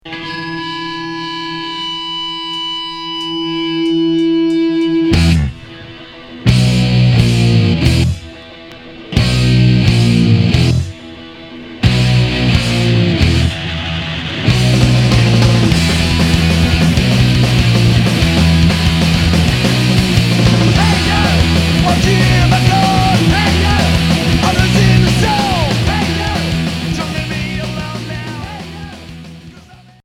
Hardcore Premier 45t